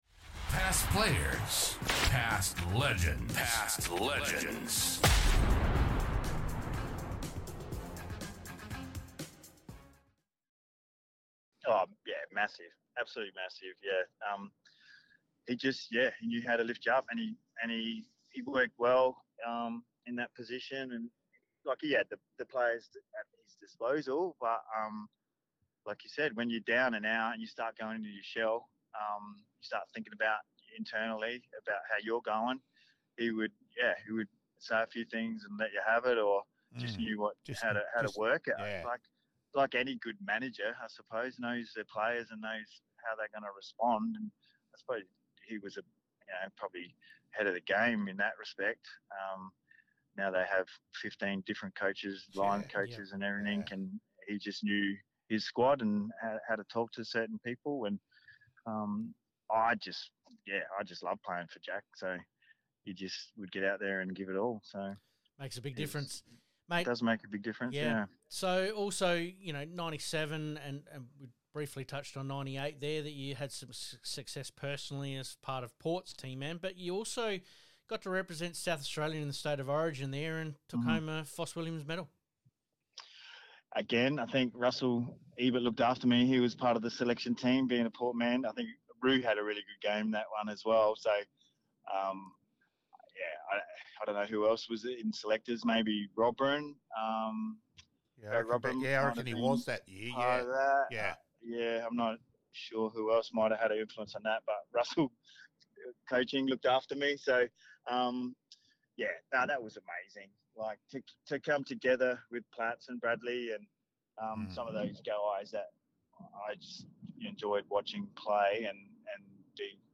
Past Players Past Legends - Interview (only) with some of our special guests